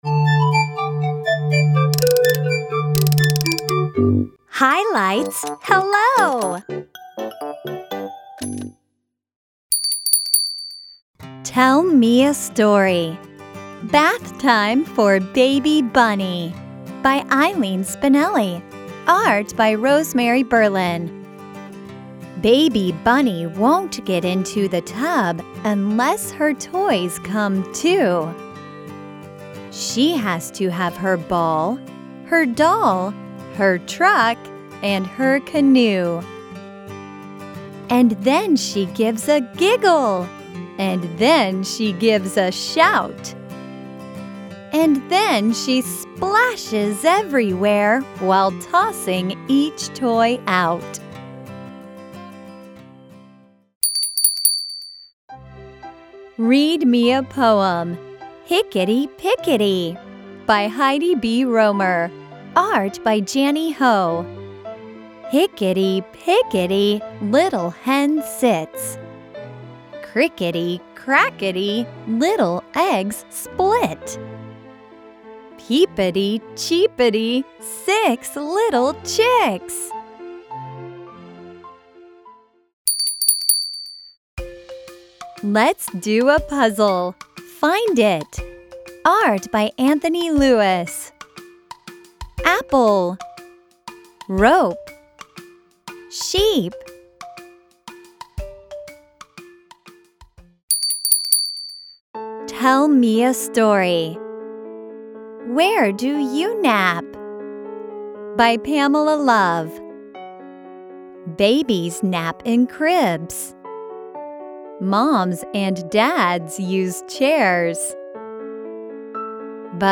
Below you will find audio narration of every book by a native English speaker.